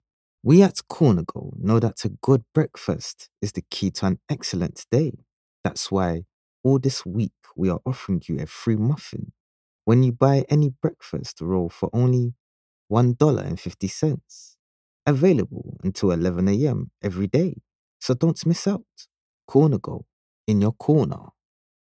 English (Caribbean)
Yng Adult (18-29) | Adult (30-50)